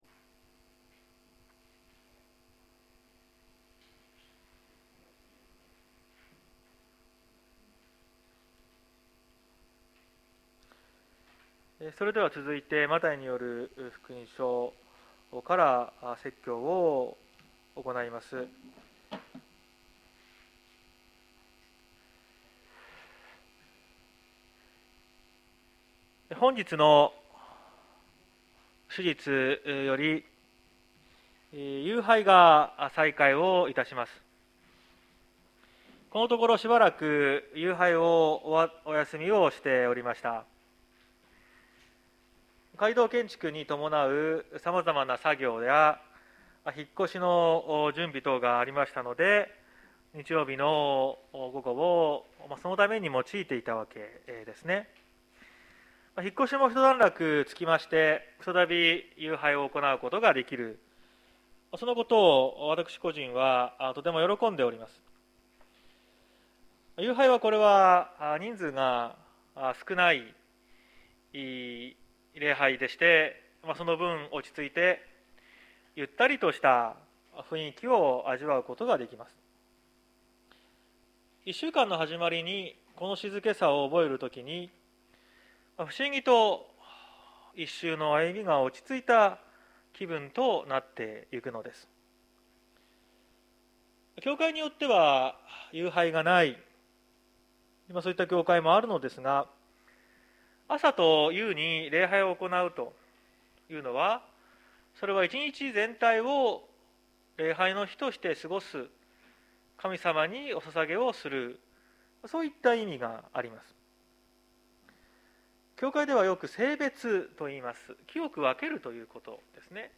説教アーカイブ。
毎週日曜日の10時30分から神様に祈りと感謝をささげる礼拝を開いています。